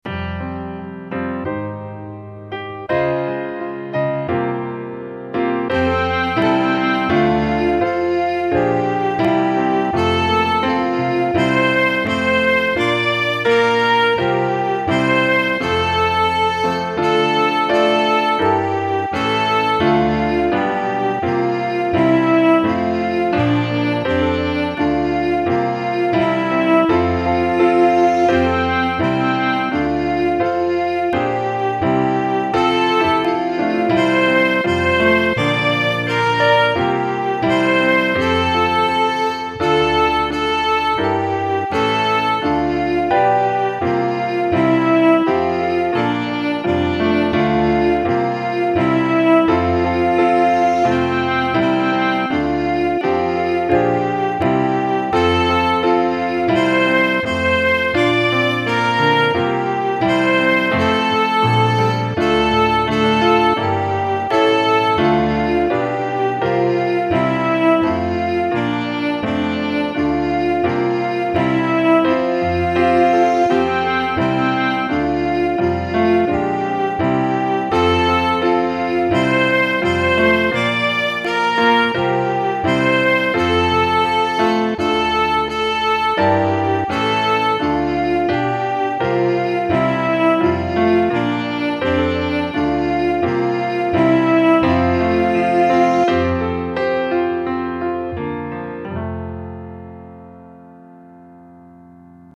My backing is just piano and faux viola.